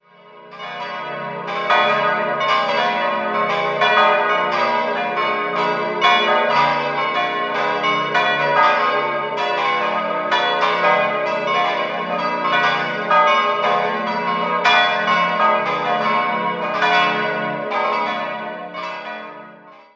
5-stimmiges Geläute: d'-f'-g'-b'-c''